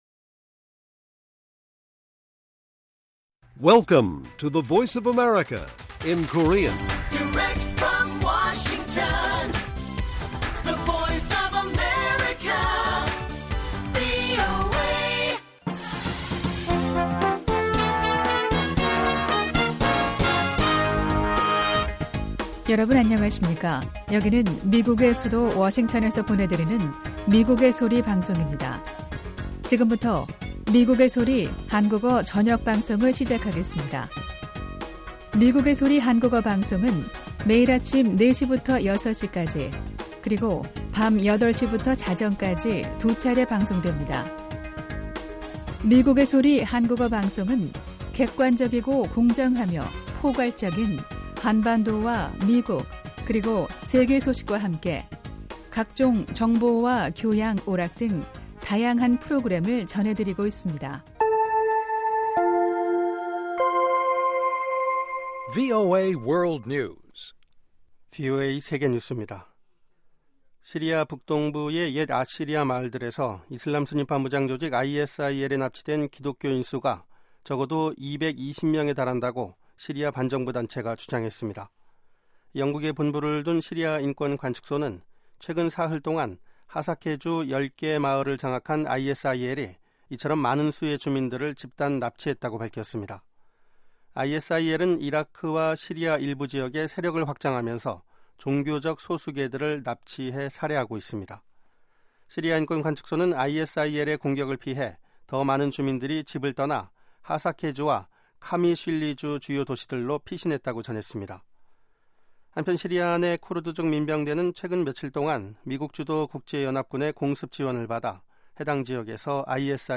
VOA 한국어 방송의 간판 뉴스 프로그램 '뉴스 투데이' 1부입니다. 한반도 시간 매일 오후 8시부터 9시까지 방송됩니다.